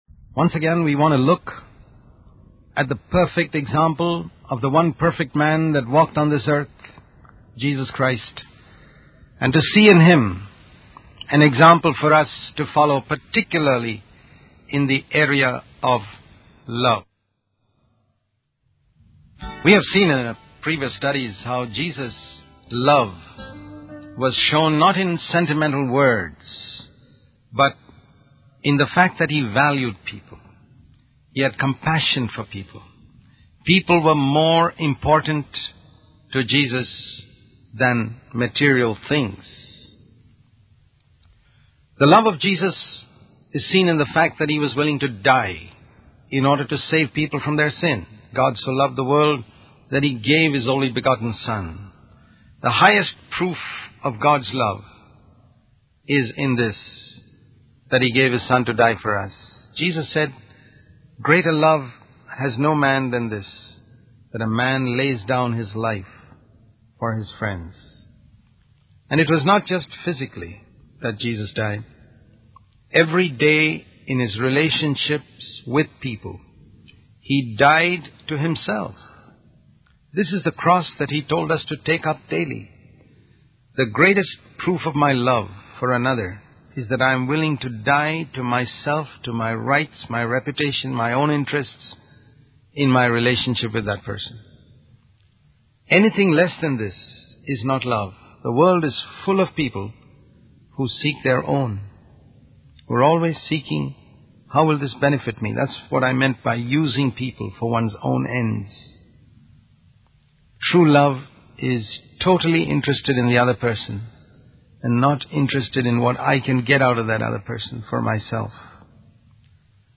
In this sermon, the speaker emphasizes the importance of love in our speech and how it reveals what is in our hearts. He questions why people belittle and hurt others with their words, suggesting that it may be because they lack the love of Christ. The speaker then highlights Jesus as the perfect example of love, showing how he valued people and had compassion for them.